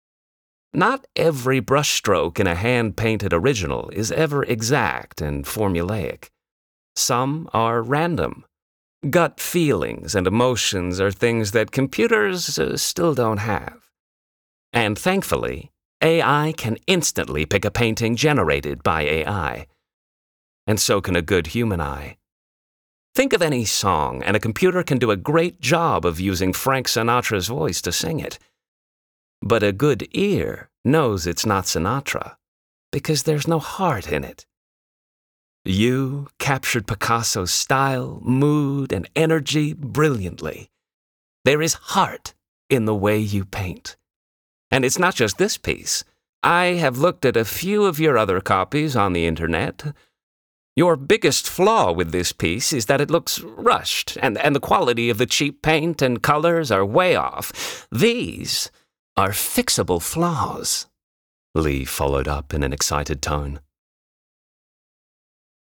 Audiobook_US Accent_Character